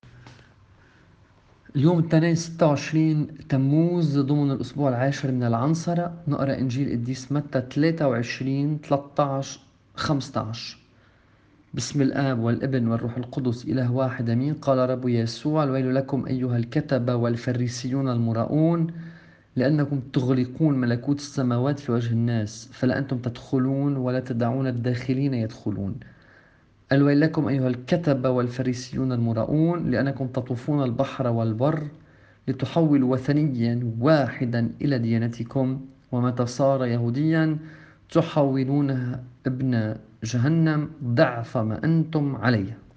الطقس الماروني